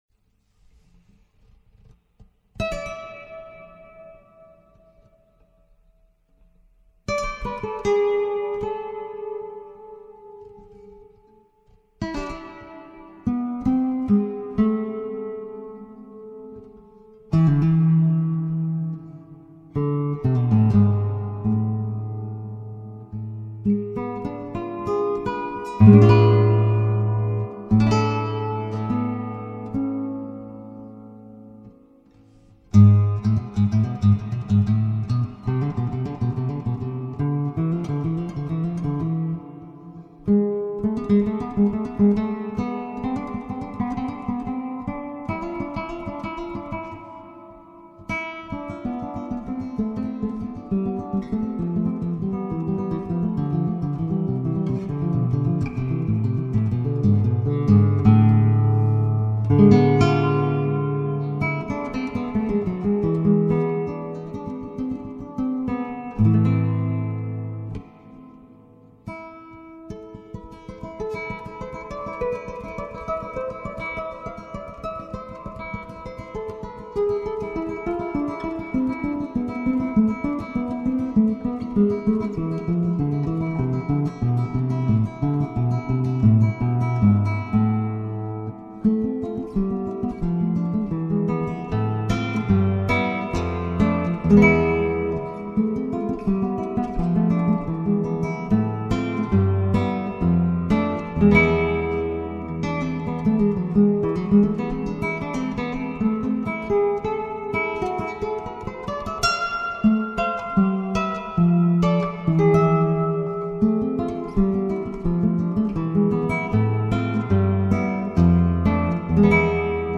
My son records picked classical guitar about 1/2mtr from a pair of Lewitt SDCs onto a Lenovo laptop and get a very decent S/N ratio.
That's the last thing I had from him you can judge noise at the end.